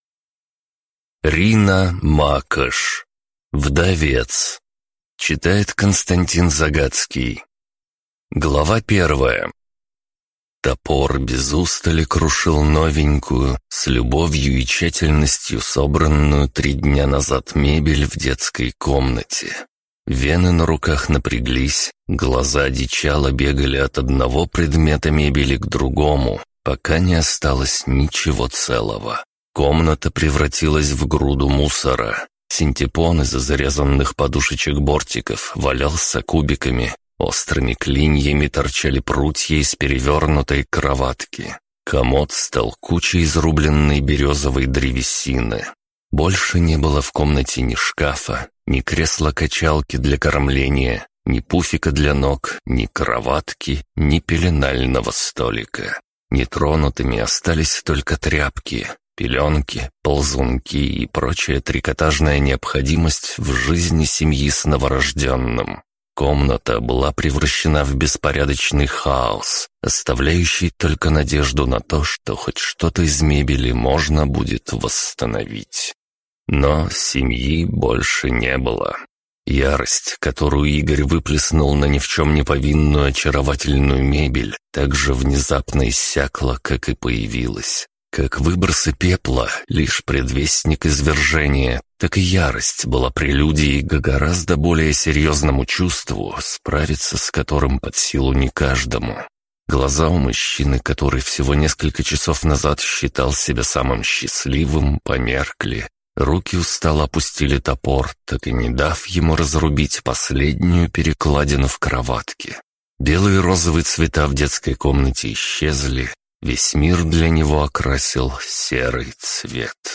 Аудиокнига Вдовец | Библиотека аудиокниг
Прослушать и бесплатно скачать фрагмент аудиокниги